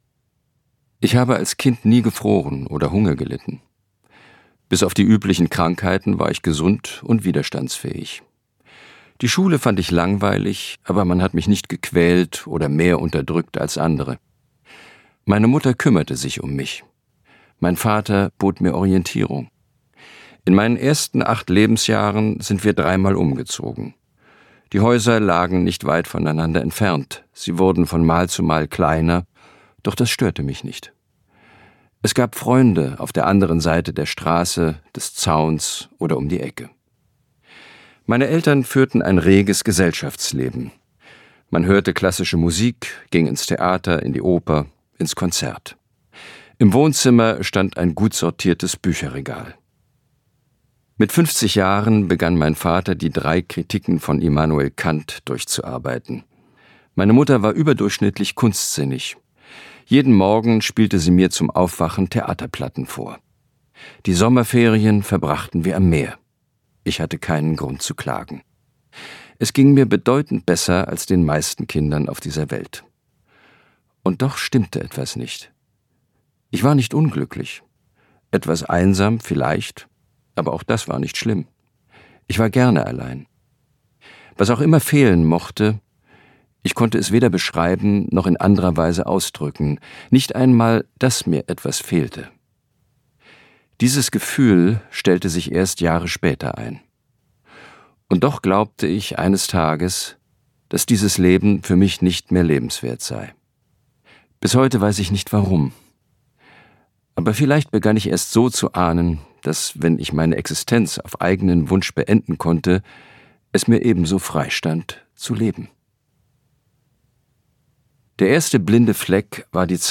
Sprecher Christian Berkel